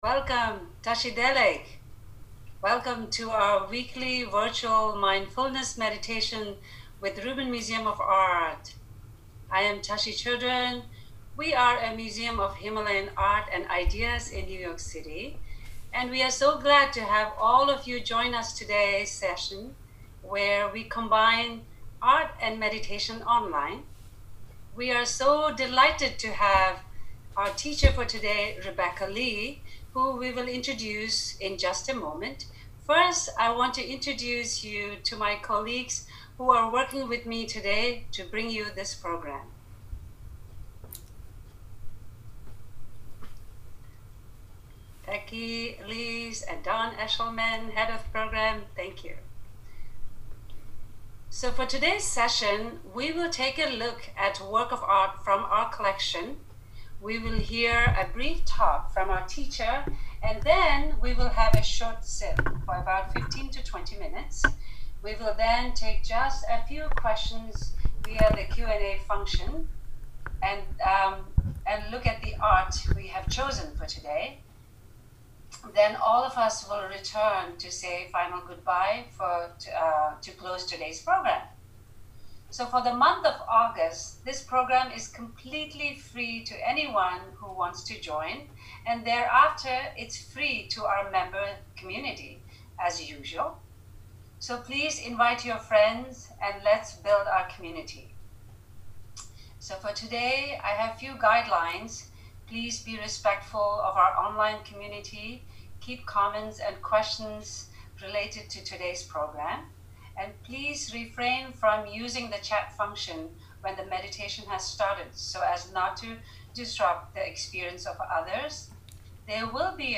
This talk was given to the Biweekly Online Dharma Group of Chan Dharma Community on March 5, 2021.